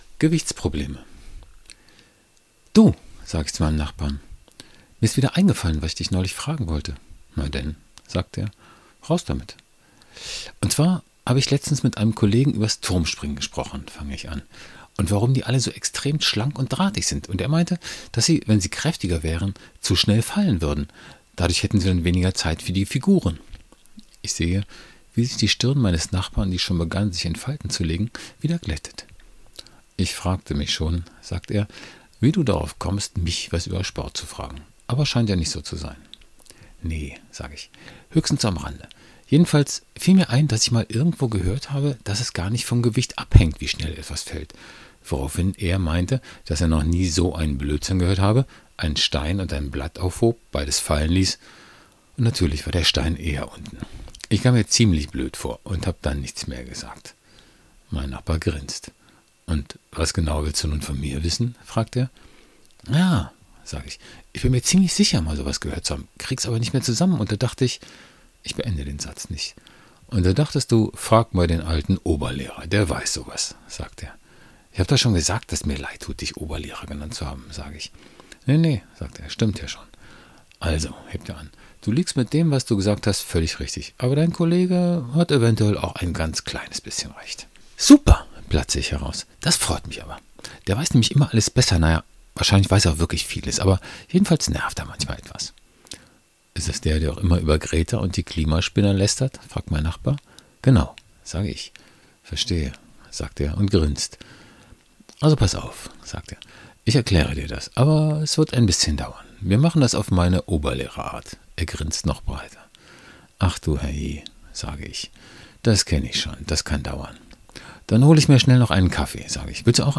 Lesung von 'Gewichtsprobleme' - Direkt: audio/gewichtsprobleme__ings_de.mp3